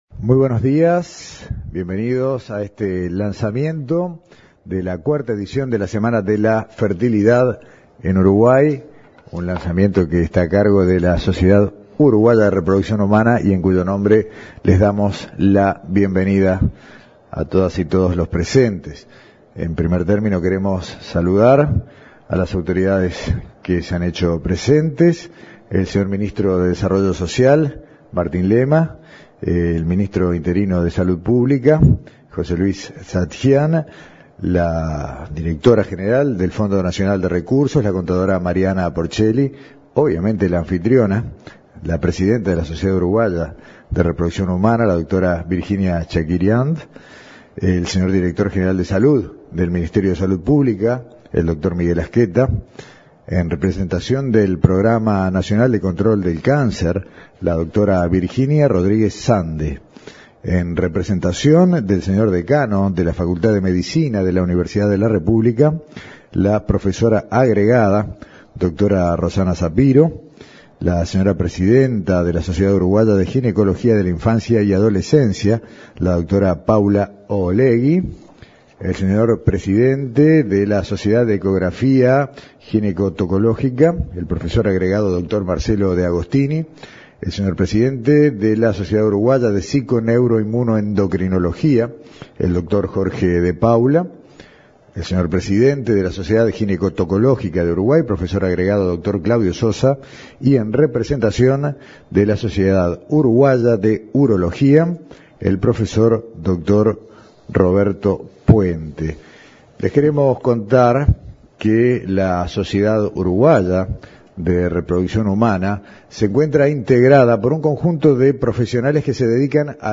Lanzamiento de la cuarta edición de la Semana de la Fertilidad